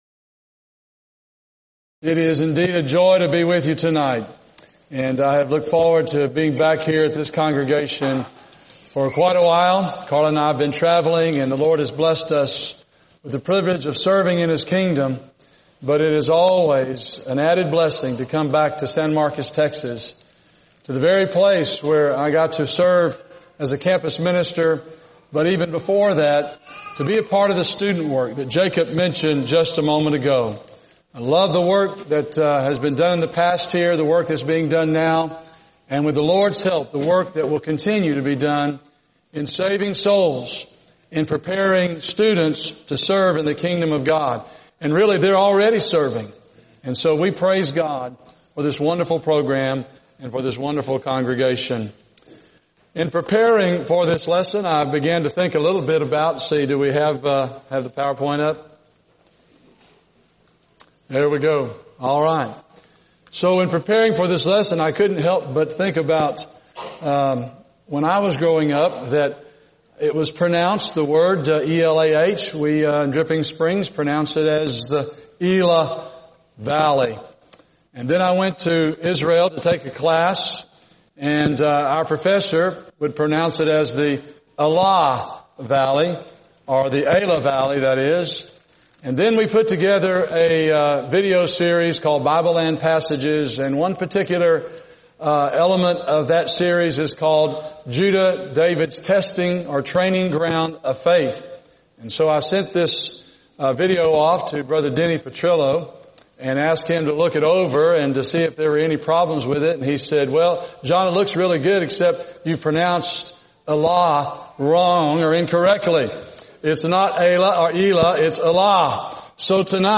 Event: 2018 Focal Point Theme/Title: Preacher's Workshop